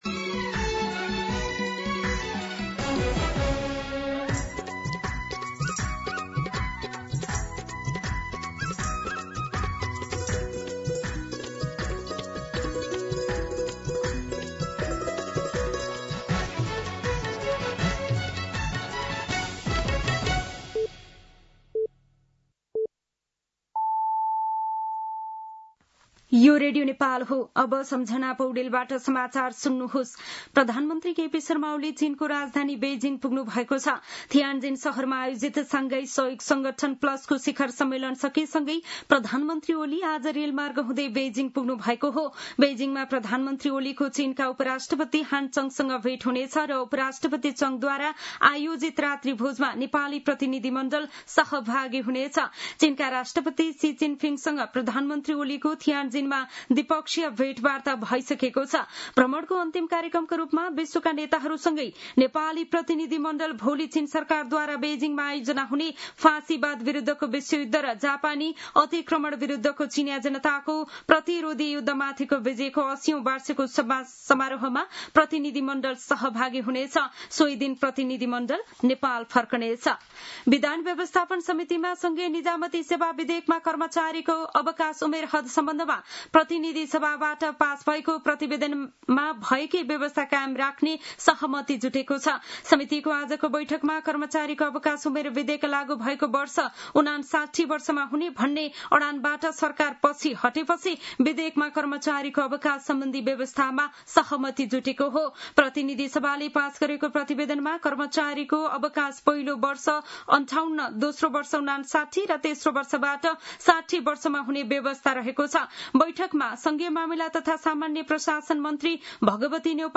मध्यान्ह १२ बजेको नेपाली समाचार : १७ भदौ , २०८२
12pm-News.mp3